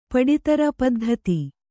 ♪ paḍi tara paddhati